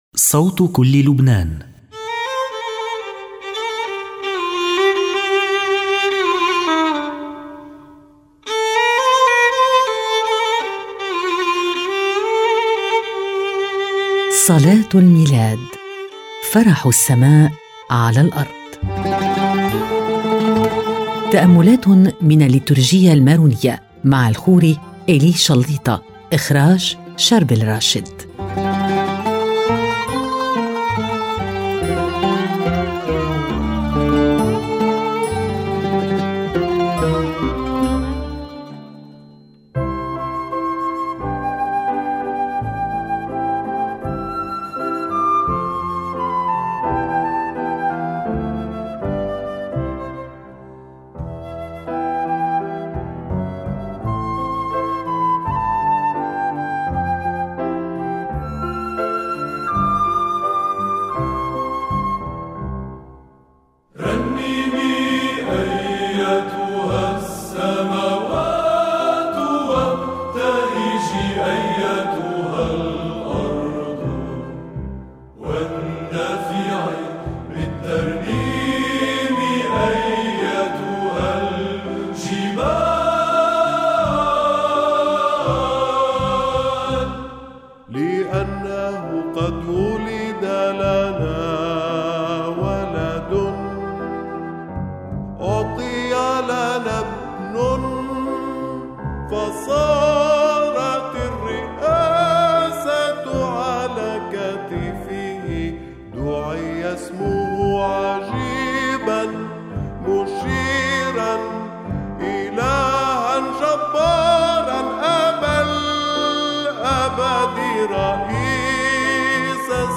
حلقات خاصة صلاة الميلاد - فرح السماء على الارض Dec 26 2024 | 00:39:03 1x 00:00 / 00:39:03 Subscribe Share RSS Feed Share Link Embed